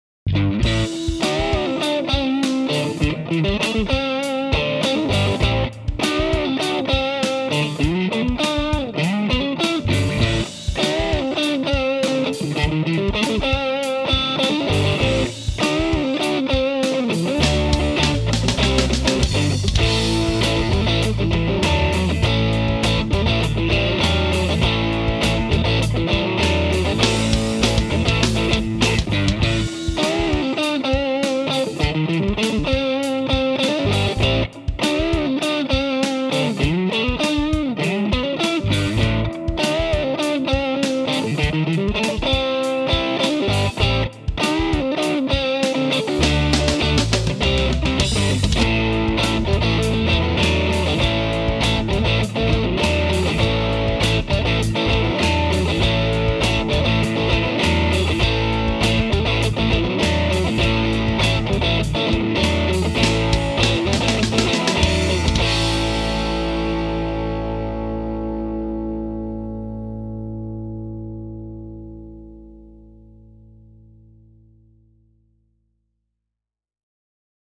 Then I started playing this little funky riff that I just laid down.
But the Abunai 2 really smoothens out the drive, and now I’m diggin’ playing with some real grit on my Strat.
abunai2-with-strat.mp3